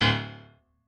piano10_9.ogg